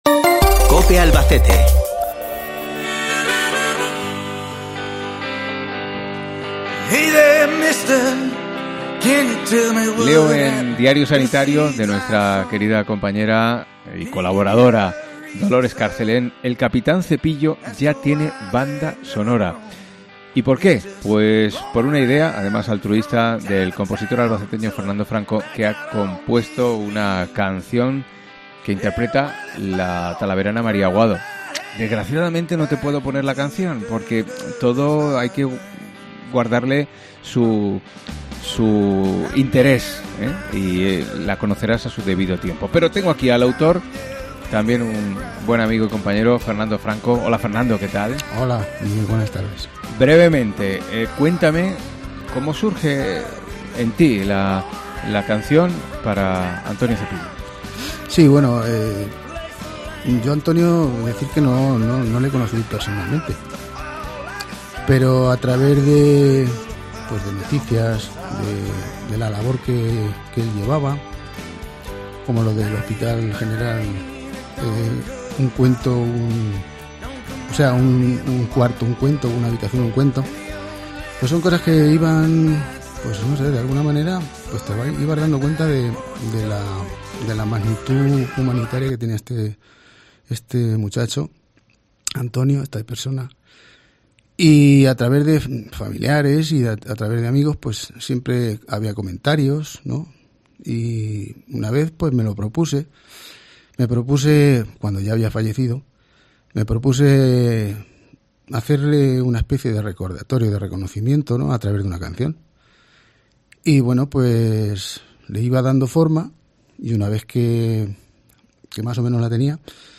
ENTREVISTA COPE